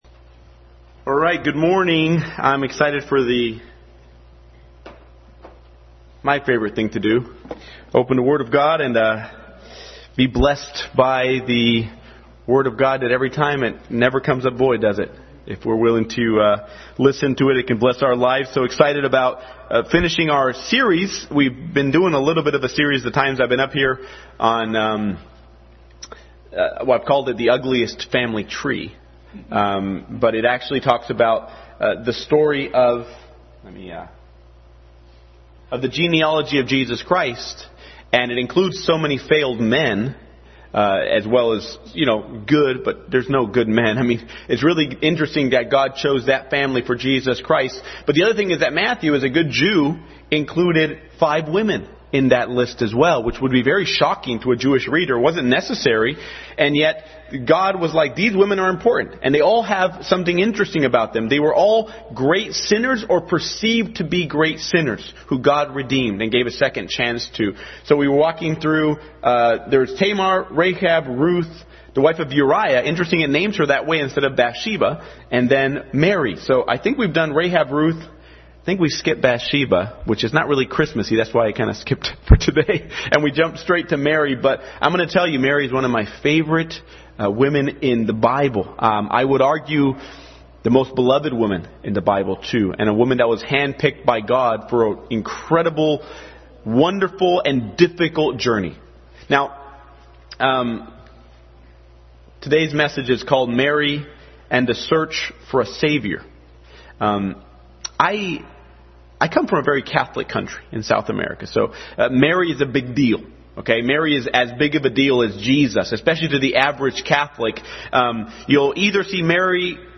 Passage: Luke 1:28-38, 2:41-50, Matthew 1:18-24, 12:46-50, John 2:1-12, Service Type: Family Bible Hour